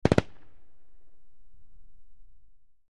Ak-21 Machine Gun Burst From Distant Point of View, X5